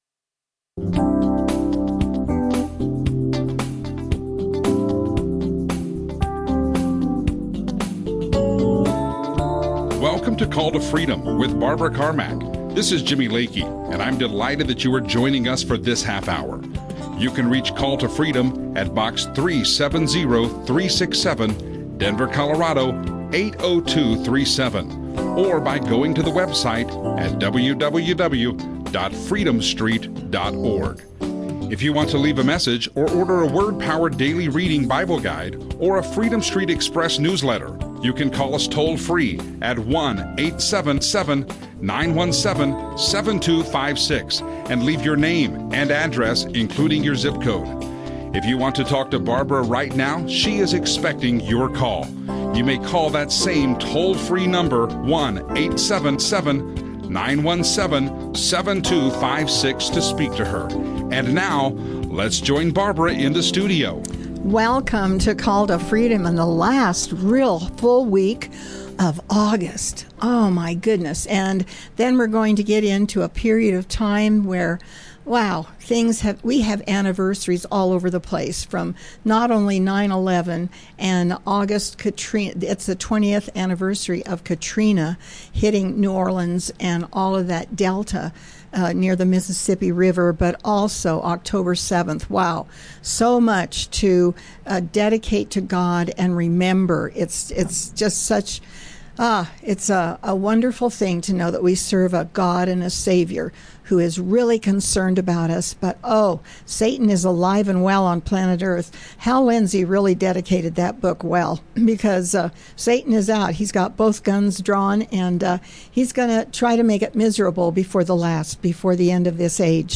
Christian radio